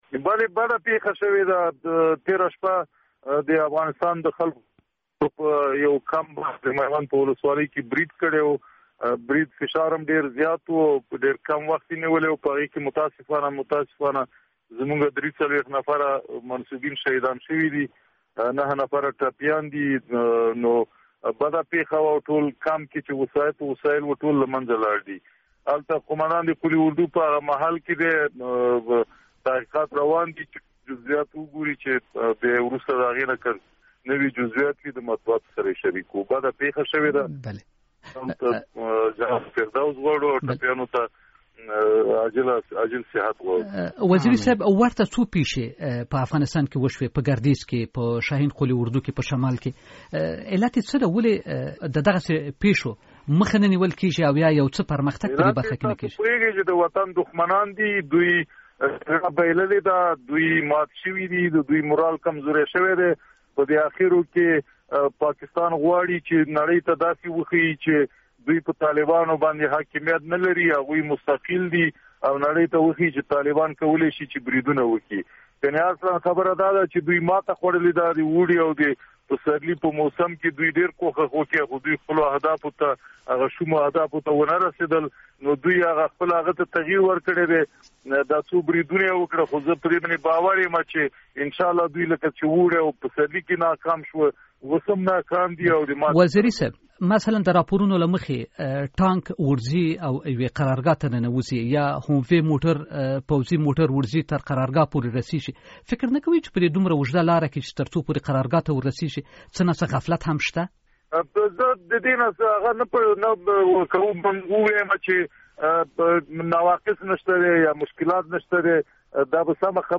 مرکه
له جنرال دولت وزیري سره مرکه